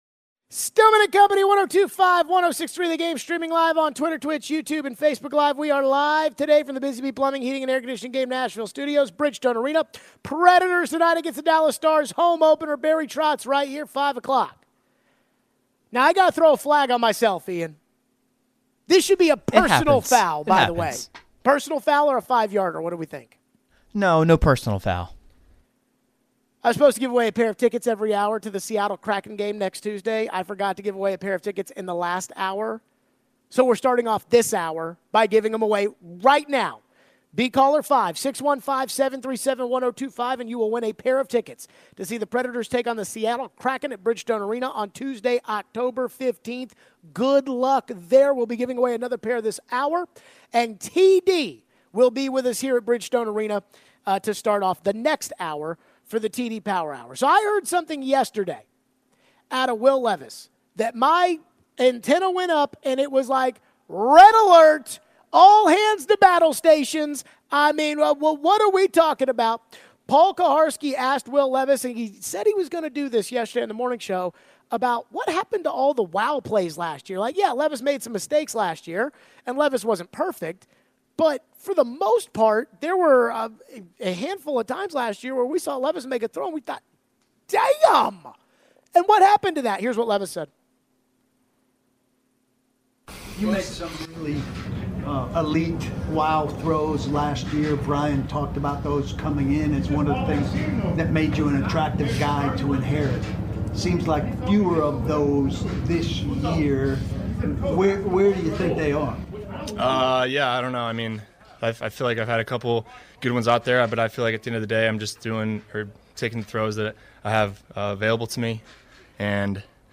Taking your calls and texts on Will Levis and the Titans.